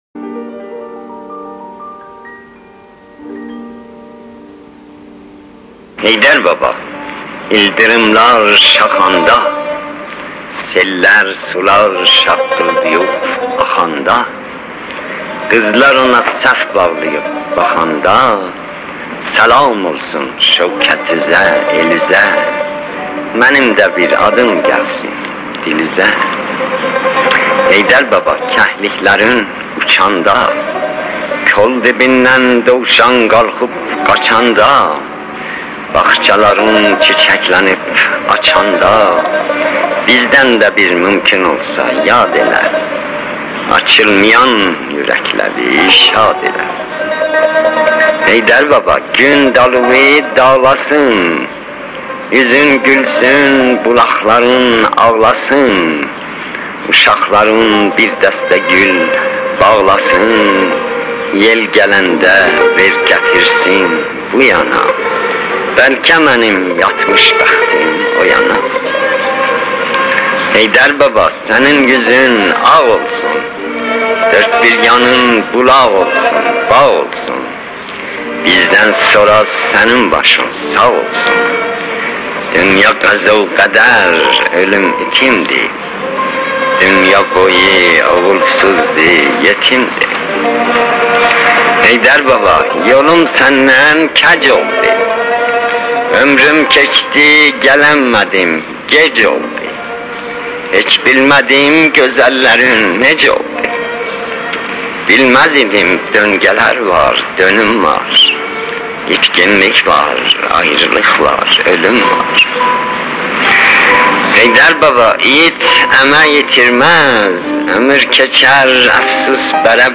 اطلاعات دکلمه
گوینده :   [شهریار]